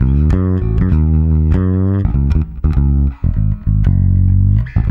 -JP MEAN D#.wav